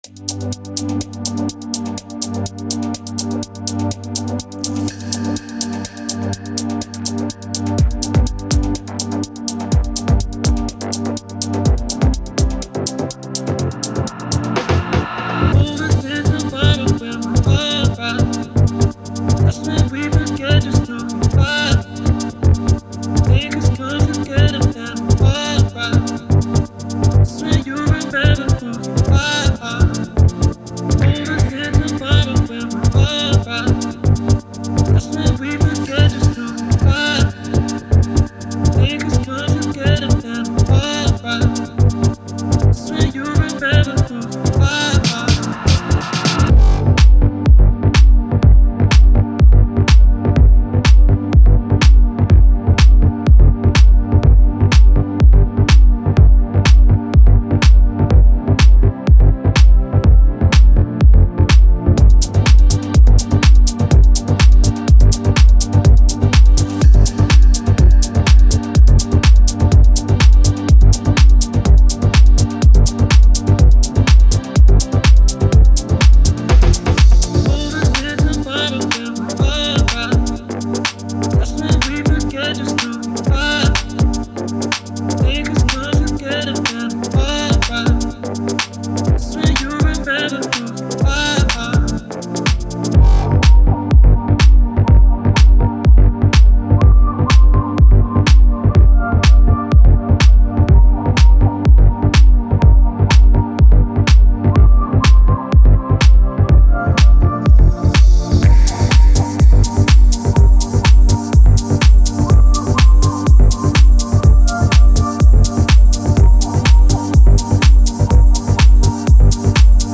Electro, House, Techno.